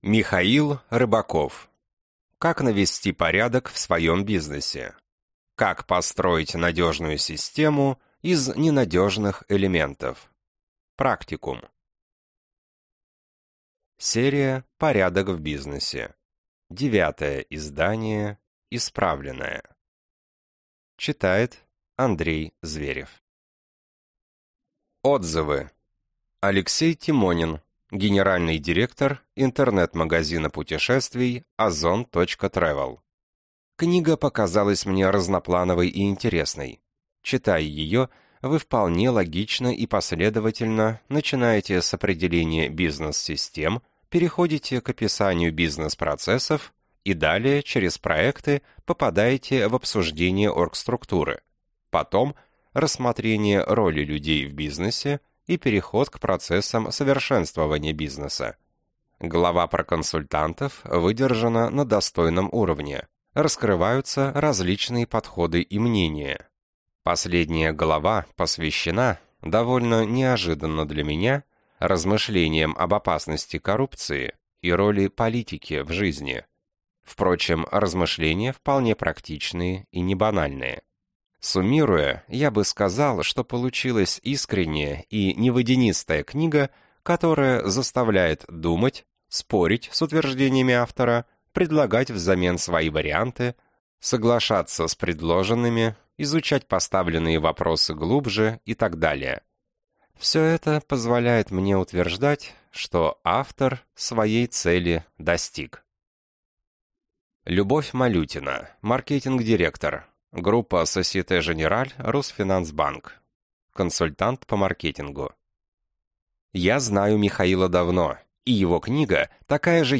Аудиокнига Как навести порядок в своем бизнесе. Как построить надежную систему из ненадежных элементов. Практикум | Библиотека аудиокниг